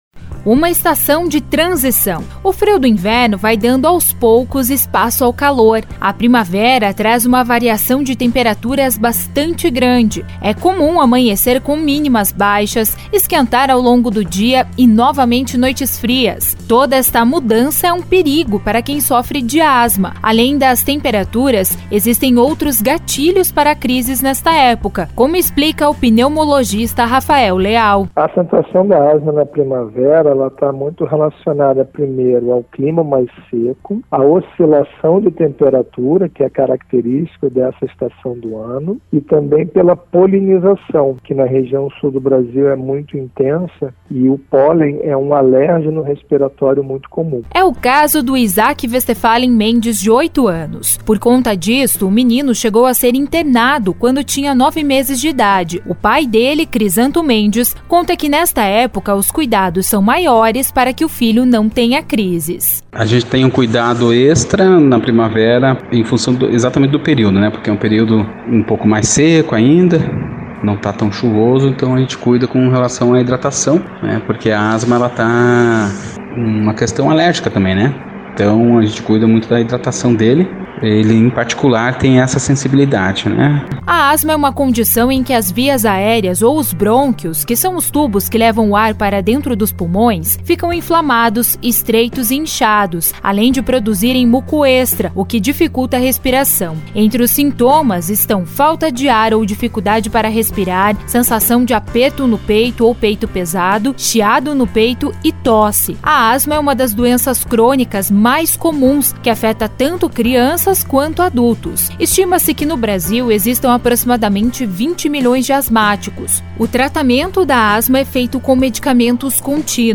Reportagem 03- Asma